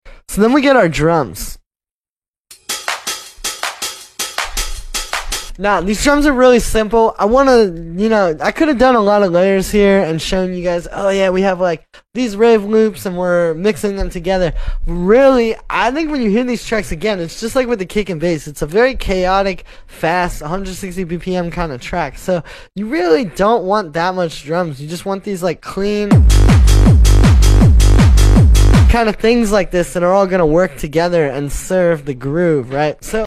Simple drums keep the chaos